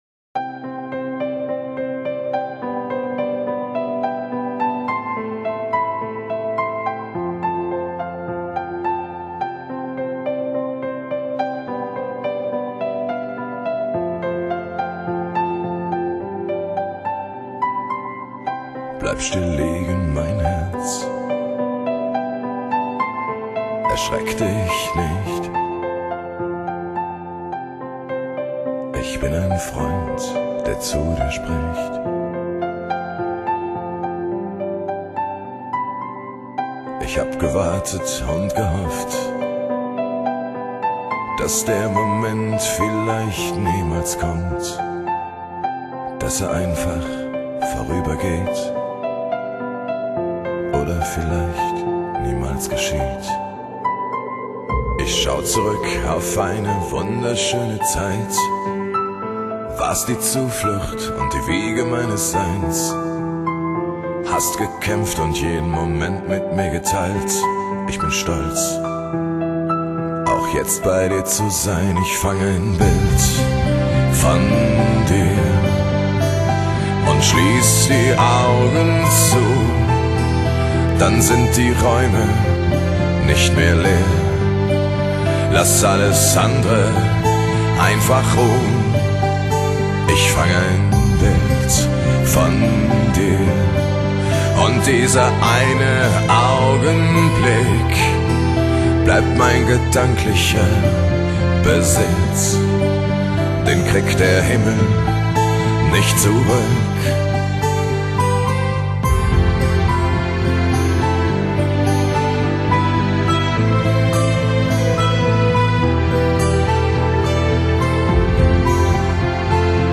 低沉的男声，轻诉的钢琴